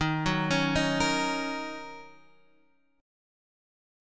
D#m7 chord